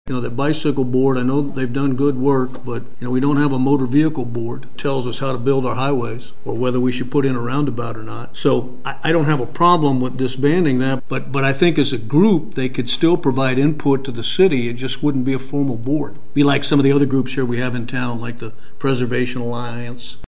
That’s Commissioner Wynn Butler at Tuesday’s city commission work session.